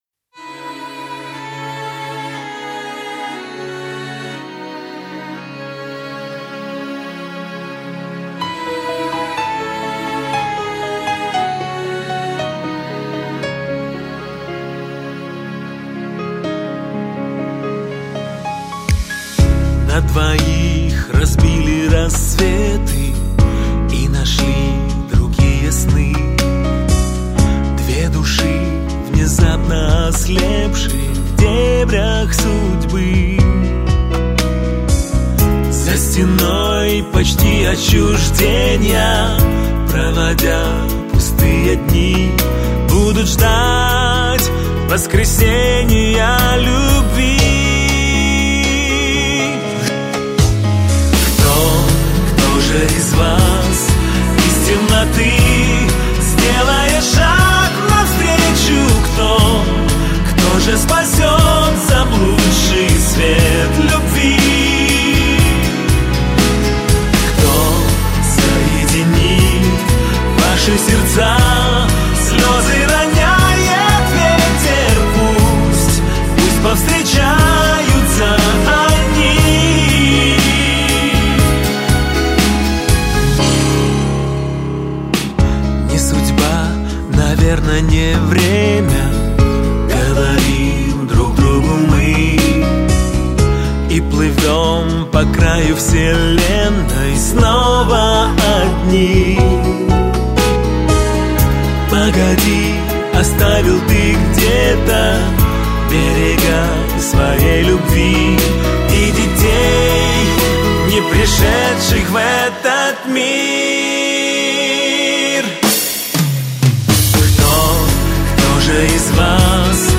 это мощная и эмоциональная композиция в жанре рэп